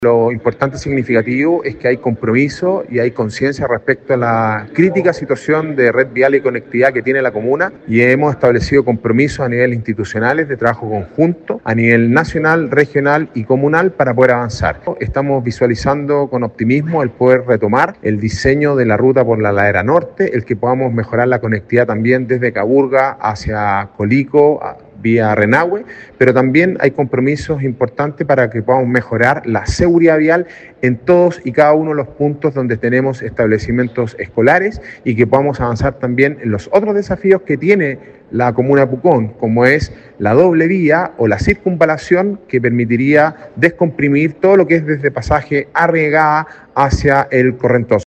Alcalde-Sebastian-Alvarez-detalla-gestiones-exitosas-en-el-MOP-.mp3